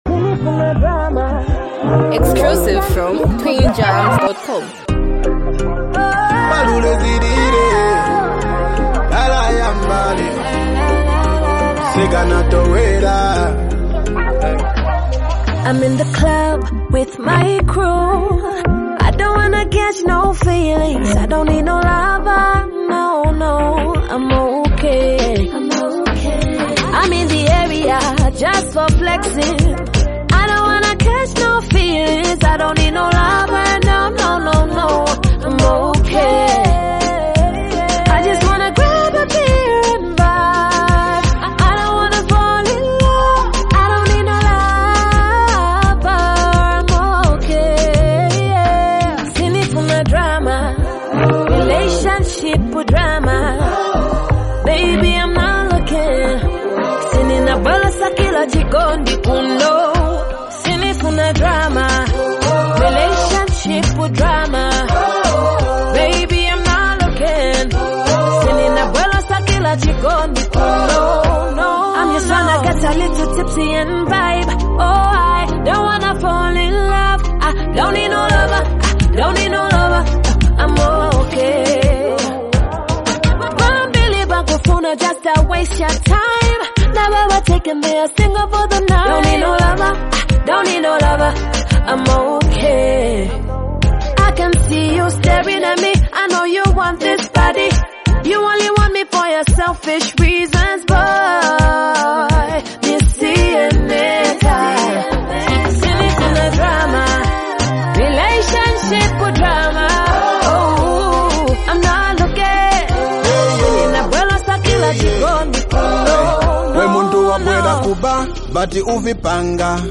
hype, love vibes, and a powerful hook
unique singing flow